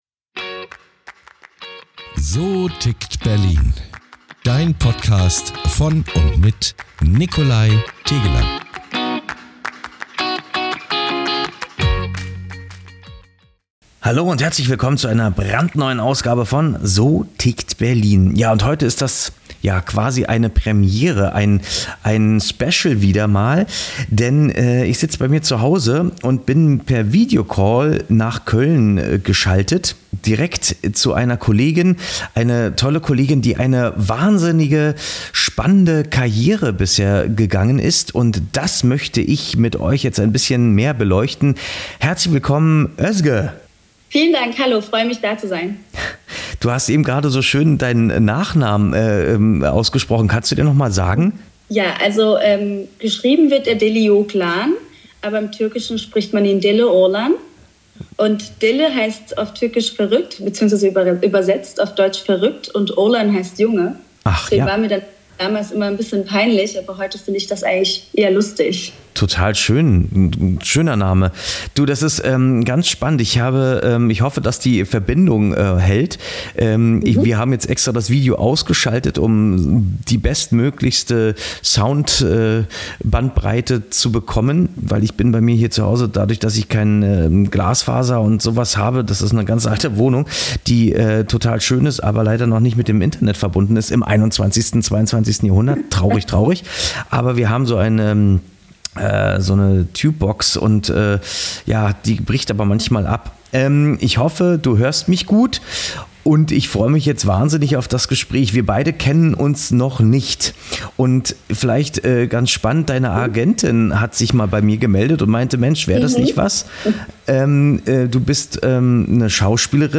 via Videocall begrüßen!